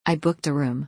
（アイブックド　アルーム）